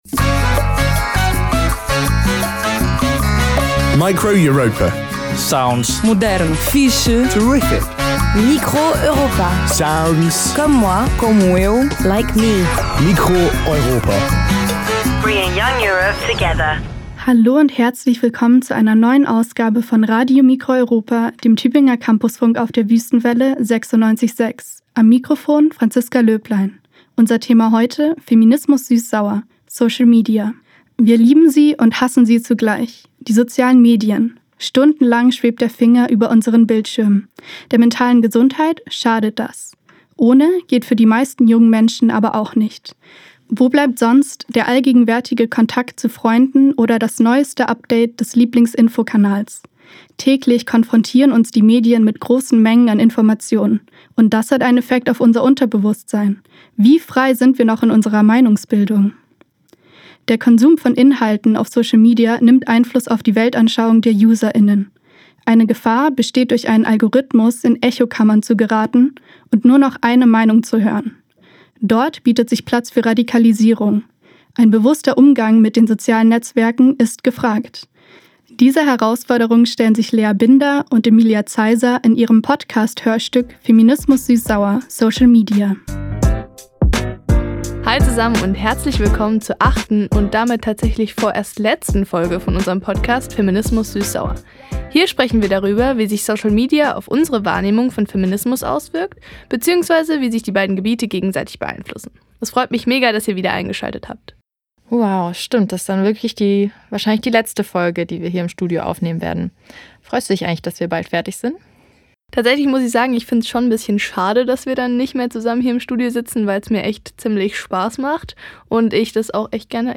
Form: Live-Aufzeichnung, geschnitten